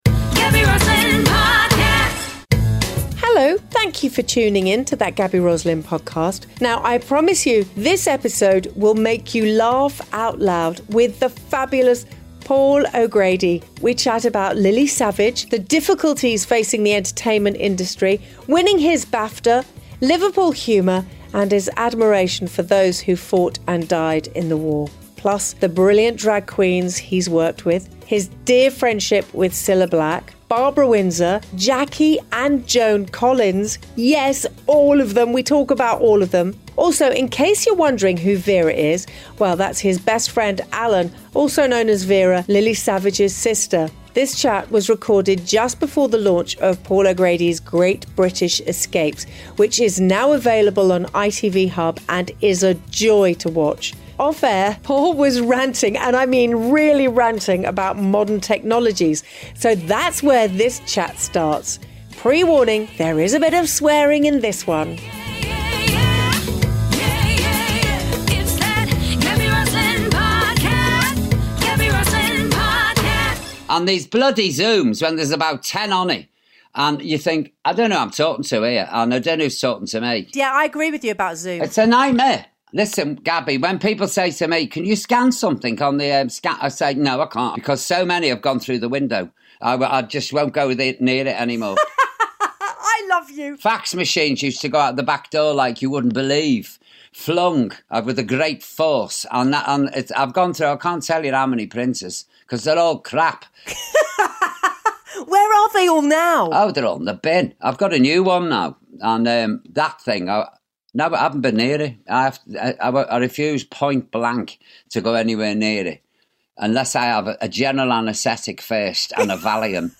In this episode Gaby chats with the fabulous Paul O’Grady and there are a lot of laughs! He chats about Lily Savage, the difficulties facing the entertainment industry, winning his BAFTA, Liverpool humour and his admiration for those who fought and died in the war. Plus, he recalls the brilliant drag queens he has worked with and his friendships with Cilla Black, Barbara Windsor and Jackie & Joan Collins.